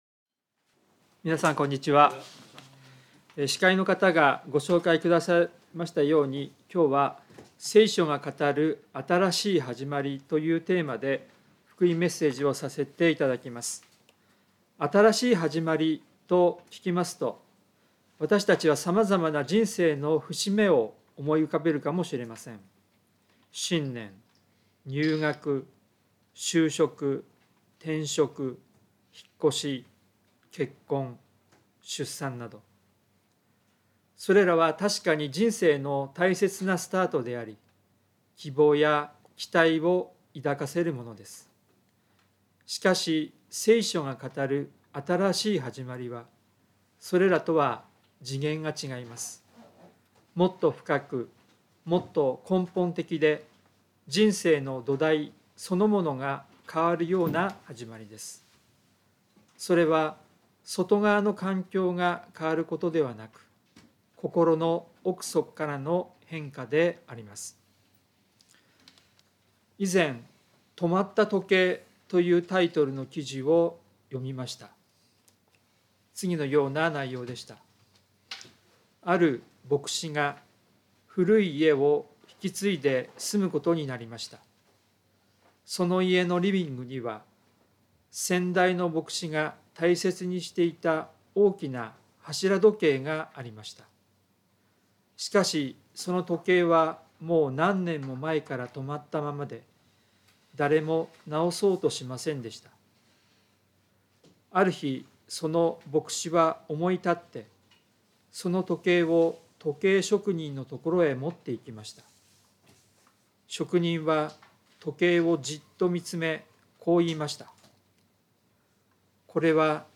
聖書メッセージ No.304